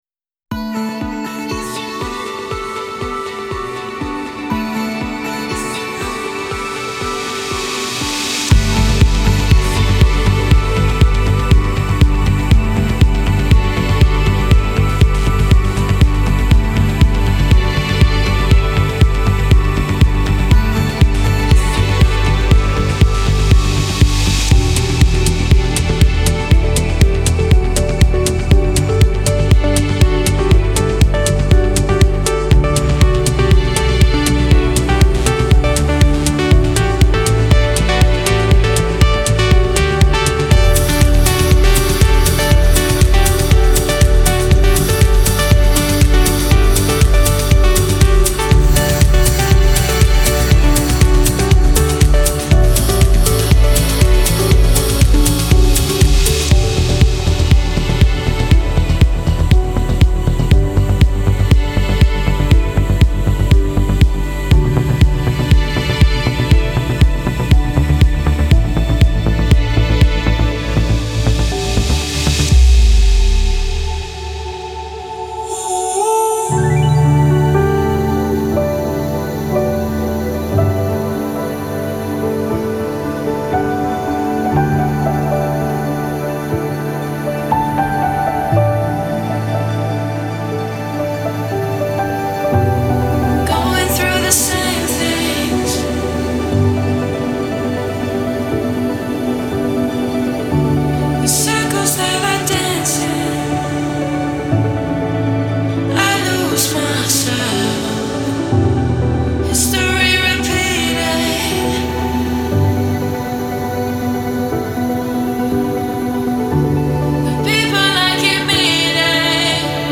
الکترونیک , ریتمیک آرام , موسیقی بی کلام